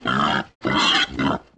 Index of /App/sound/monster/wild_boar_god
attack_1.wav